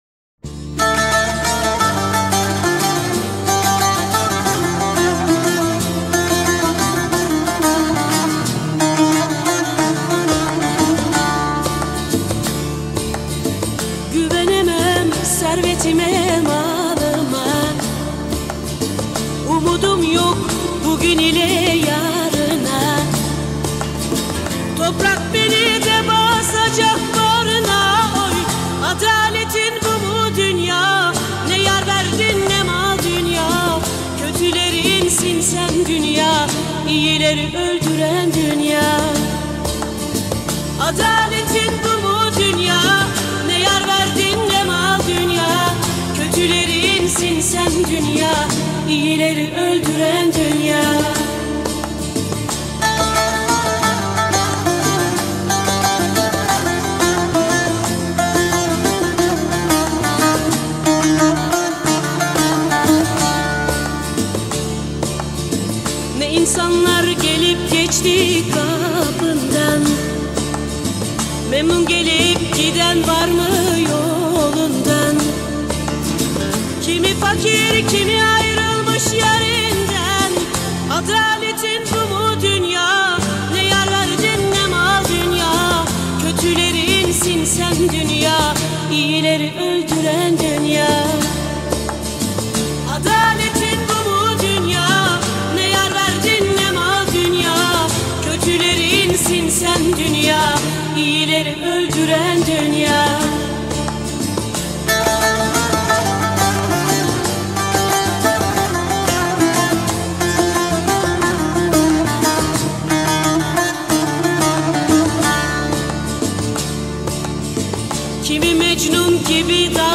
Turkish Folk Music, Anatolian Rock, Protest Music